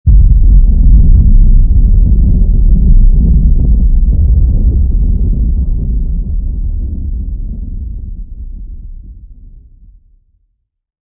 sound / weapons / bombfar.wav
bombfar.wav